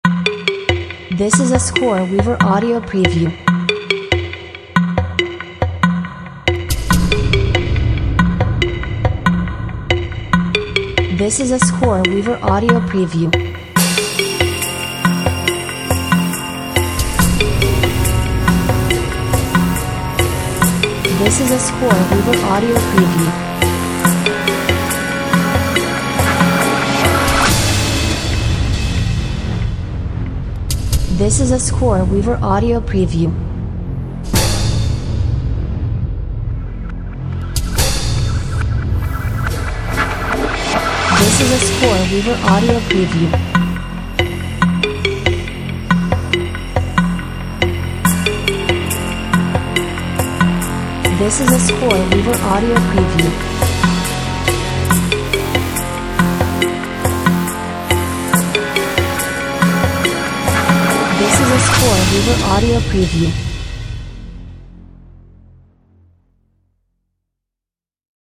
Ticking “Time and Clock”-themed Tension Music!